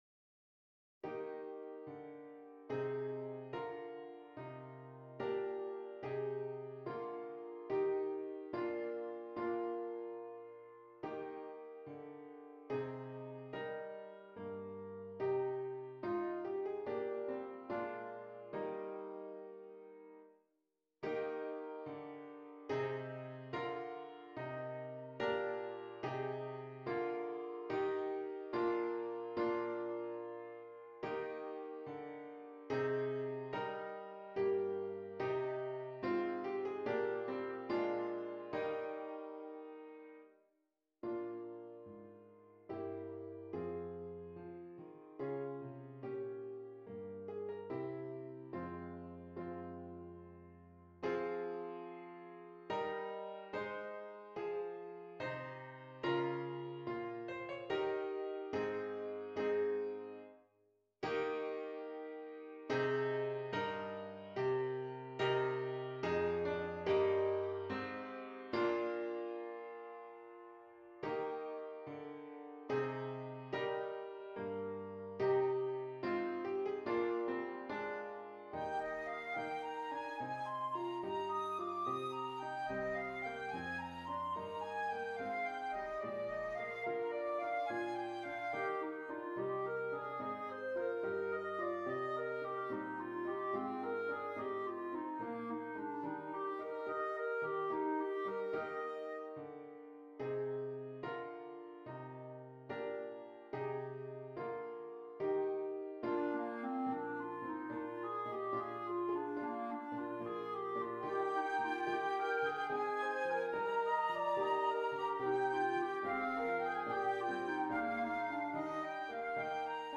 Flute, Clarinet and Keyboard